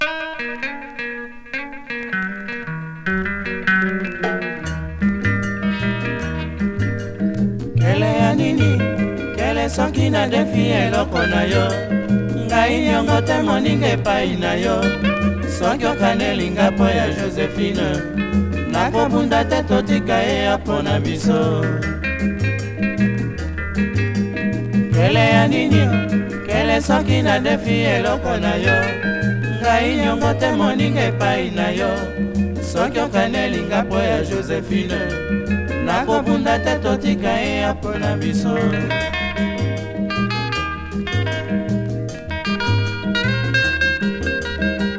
熱風とか地熱を感じるかのような心地良さと、独特の浮遊感を錯覚しそうな素晴らしさ。